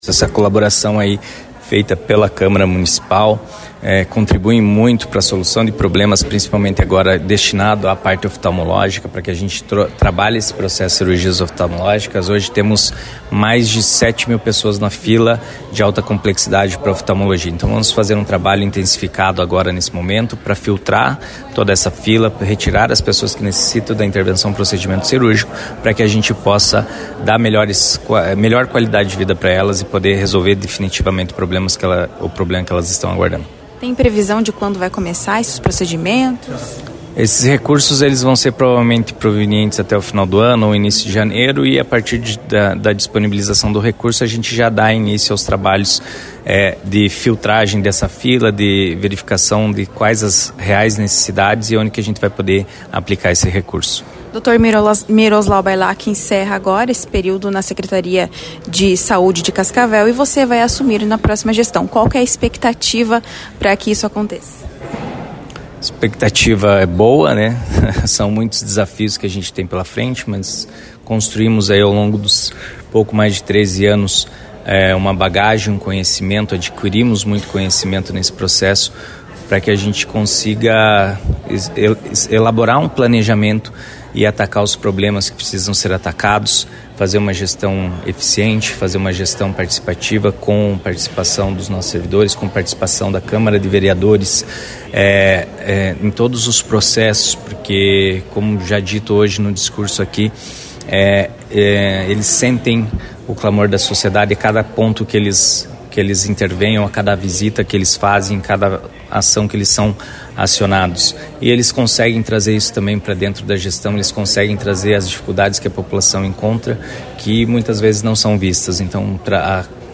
Em evento na Câmara de Vereadores de Cascavel na manhã desta quinta-feira (12), além da destinação de recursos à Saude por parte dos vereadores mediante emendas impositivas, o prefeito eleito Renato Silva anunciu Ali Haidar como secretário de Saúde para a proxima gestão, a partir de janeiro, acompanhe.
Player Ouça ALI HAIDAR, futuro secretário de Saúde de Cascavel